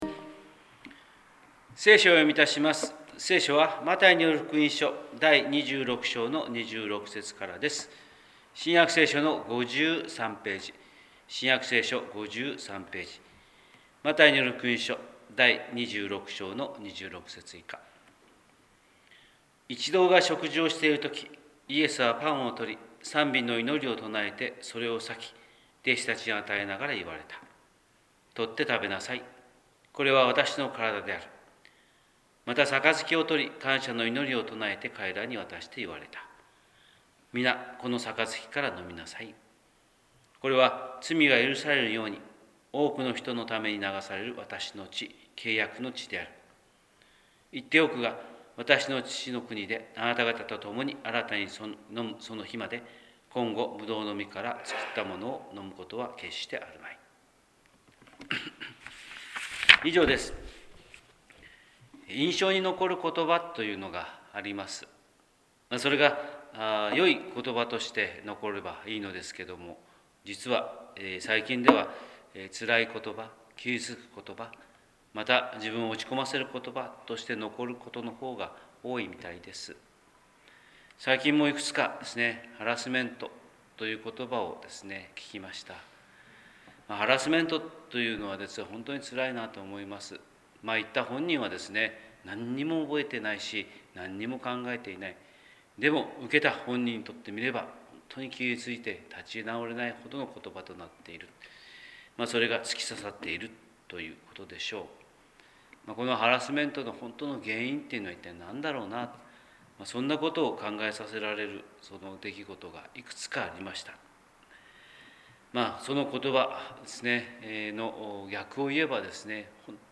神様の色鉛筆（音声説教）: 広島教会朝礼拝241106
広島教会朝礼拝241106「ことば」